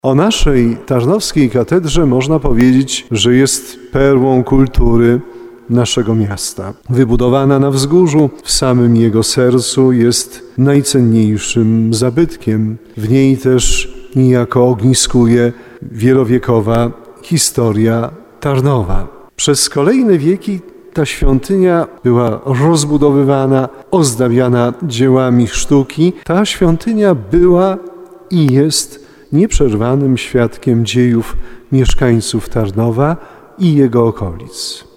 Serce diecezji - tak o katedrze mówił biskup tarnowski Andrzej Jeż podczas uroczystości rocznicy poświęcenia bazyliki katedralnej.
30kazanie.mp3